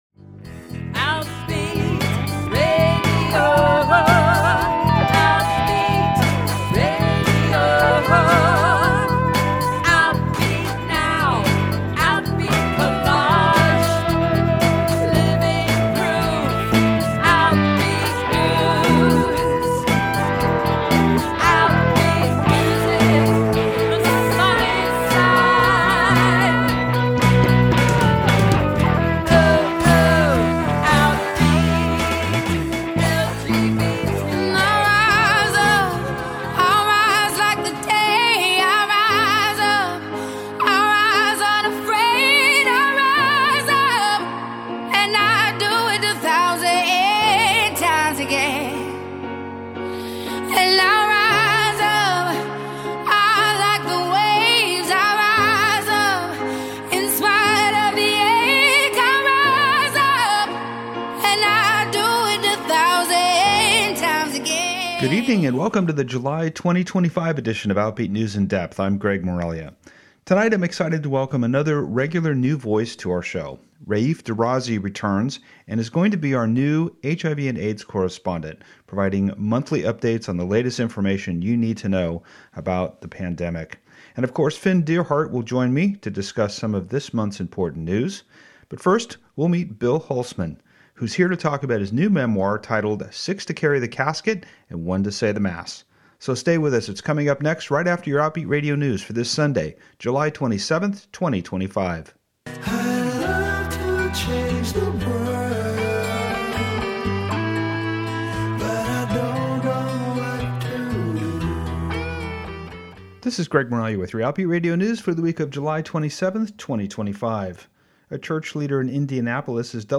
Outbeat News In Depth is a news magazine style radio show featuring a closer look at the people, events, and issues impacting the LGBT community in the California North Bay and beyond. Each show features two to four stories including the Outbeat Youth segment.